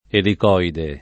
elicoide [ elik 0 ide ]